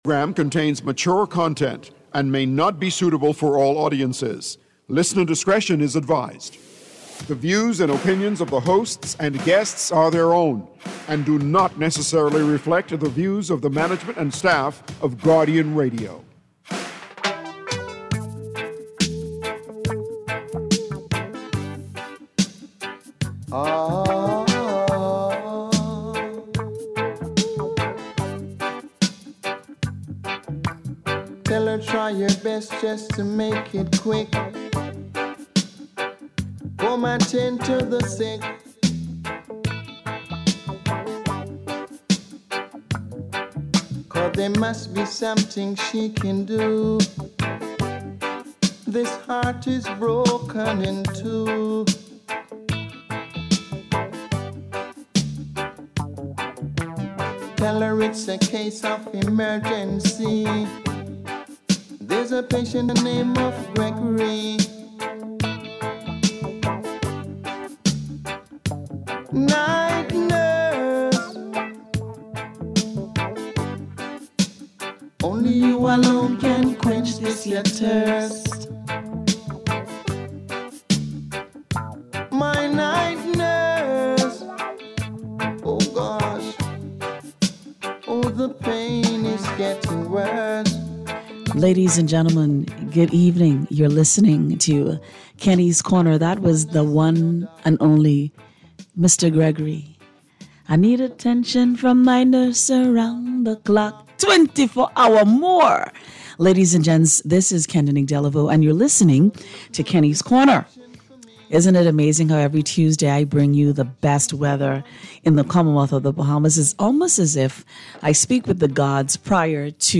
Radio Talk Show